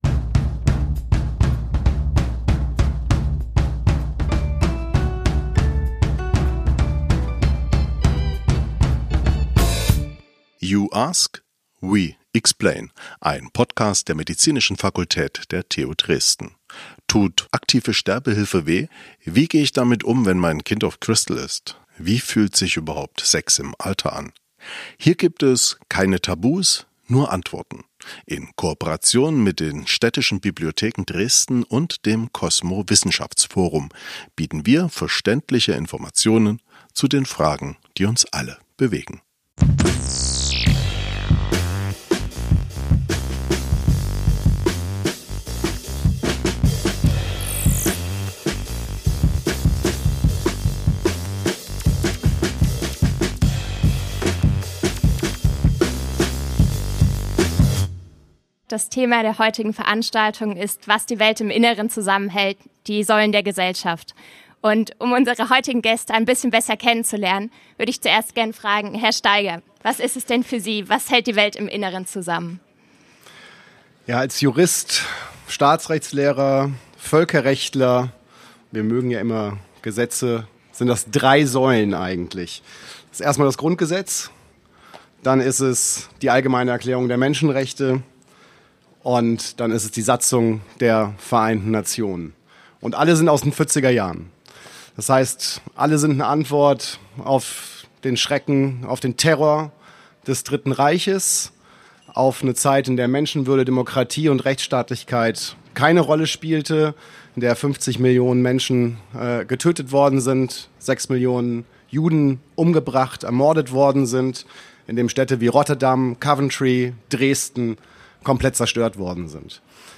Live aufgenommen
auf dem Pirnaer Marktplatz vor dem Hofladen 15.